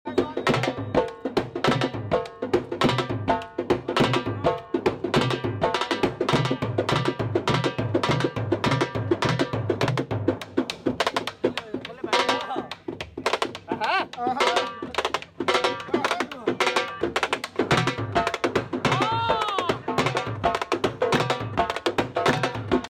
Dhol beat
Dhol program